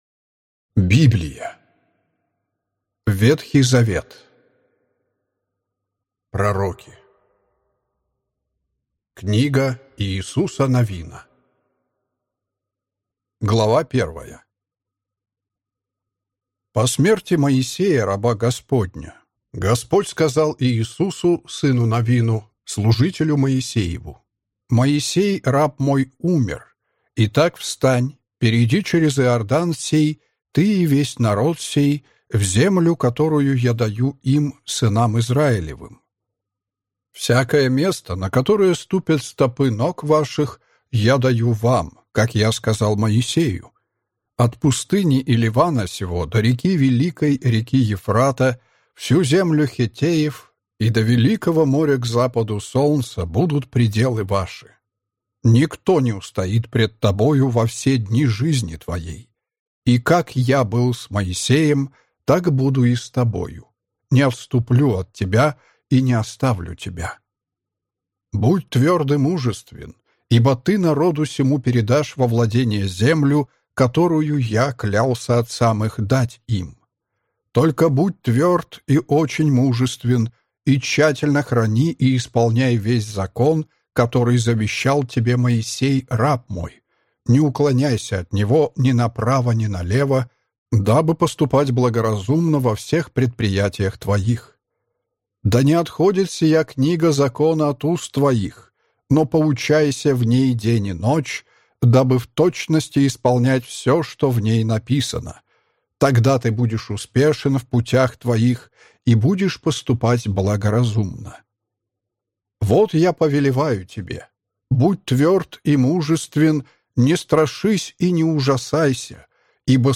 Аудиокнига Библия. Ветхий Завет (Пророки) | Библиотека аудиокниг